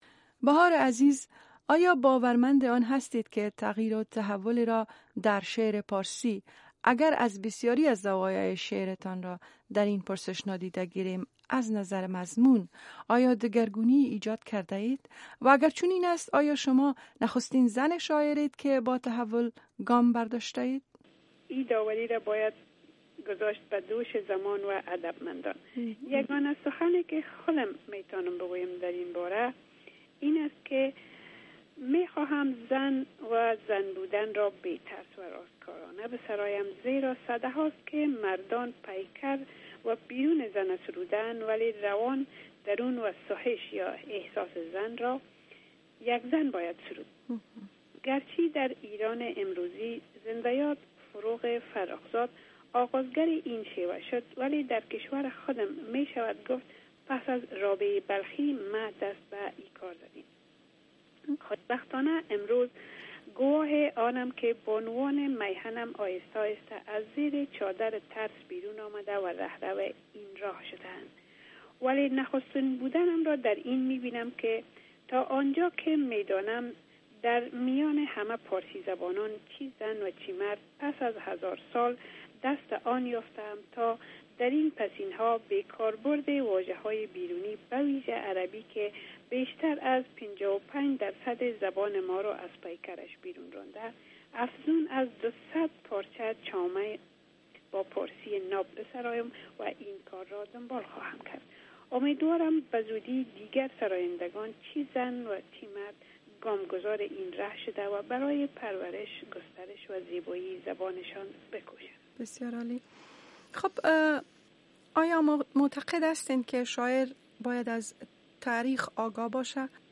interview II